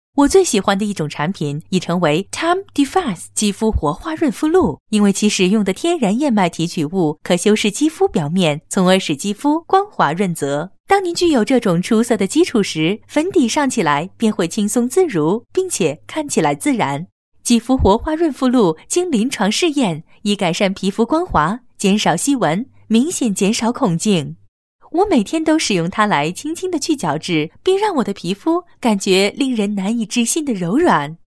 Voice Samples
female